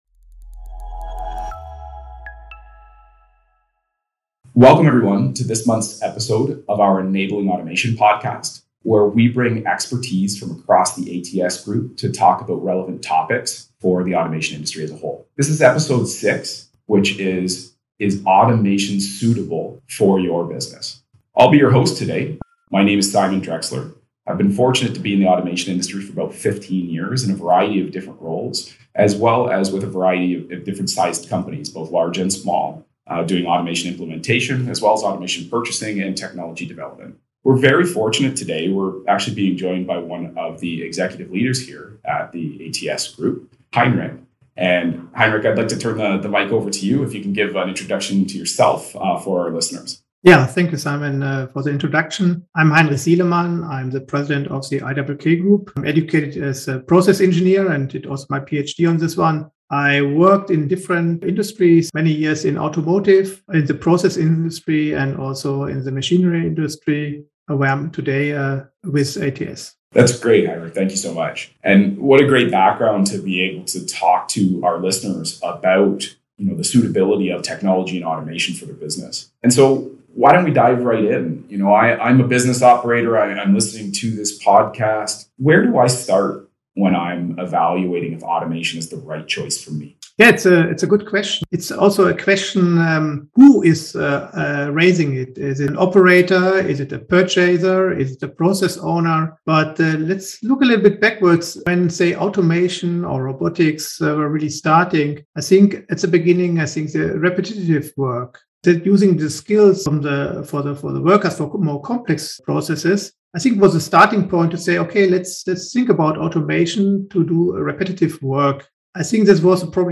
In this monthly podcast series, join industry leaders from across ATS as they share and discuss the latest industry trends, new innovations, and more!